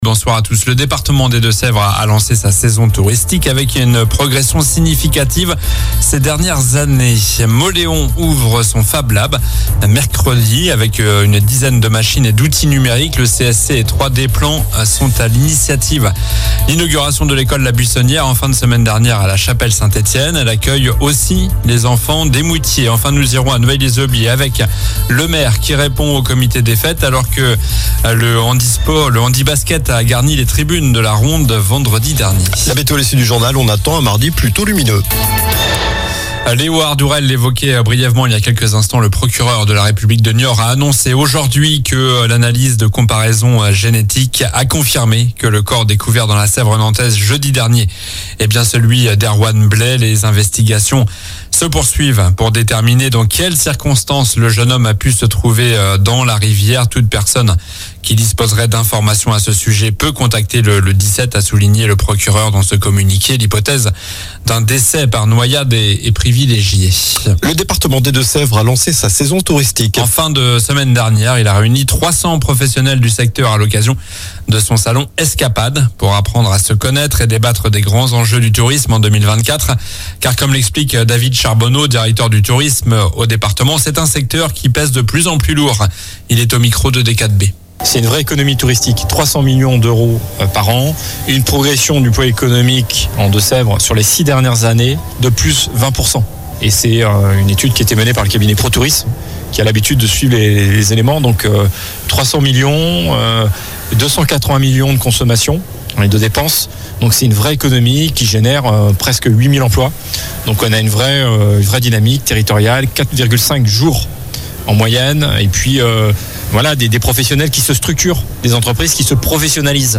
Journal du lundi 8 avril (soir)